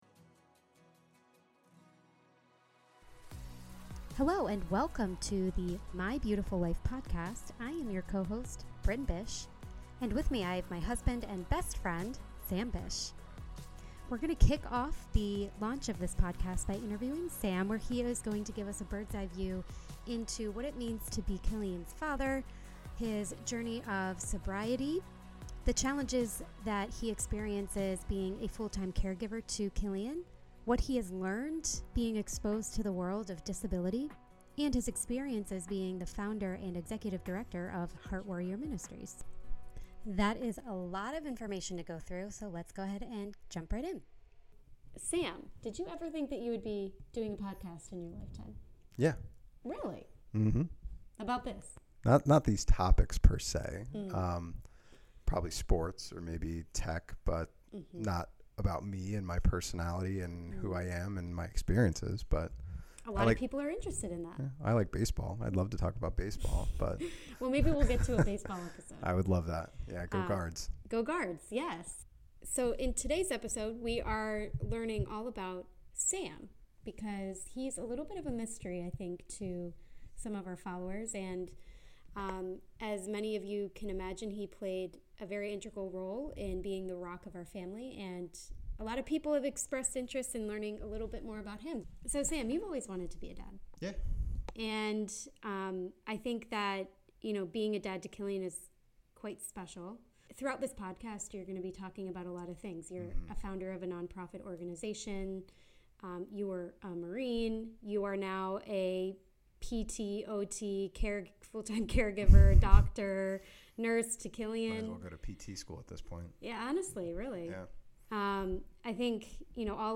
In Episode 1, “New Beginnings” we interview